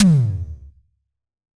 Tom_B3.wav